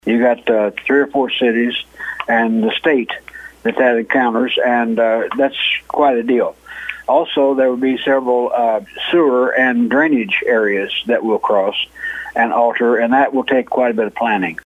St. Fancois County Presiding Commissioner, Harold Gallaher, says there are sereval aspects to getting the work started.